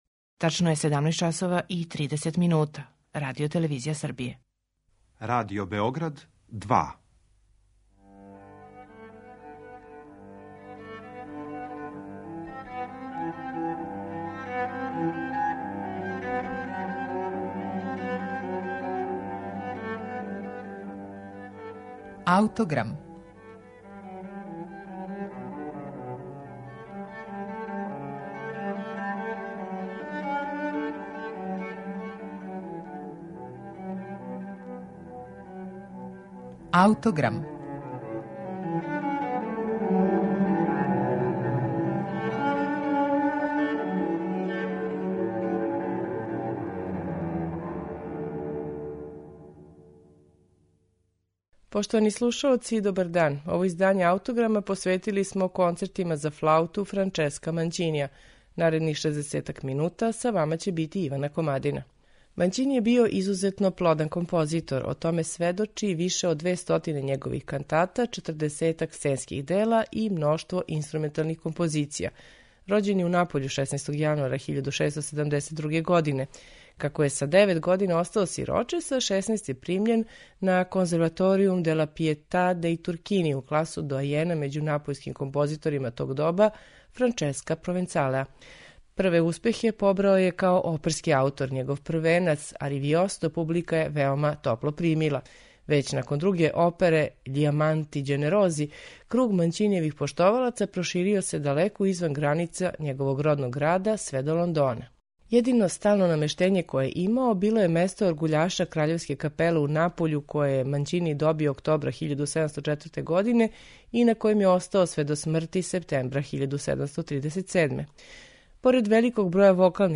Пет Манћинијевих концерата слушаћете на снимку који су на оригиналним инструментима епохе остварили
уздужна флаута
чембало